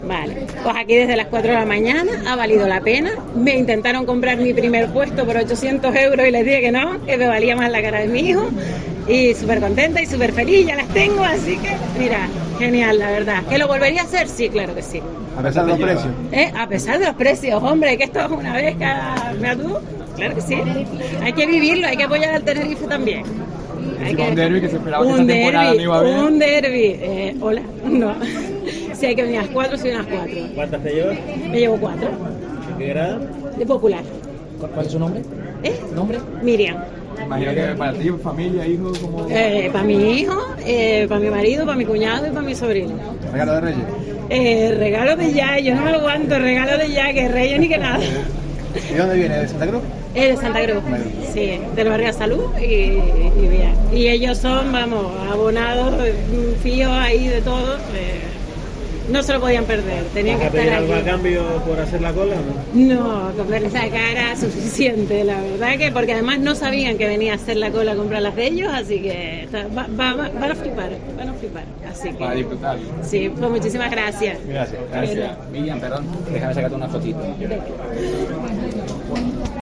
Primera aficionada en conseguir una entrada para el derbi de Copa del Rey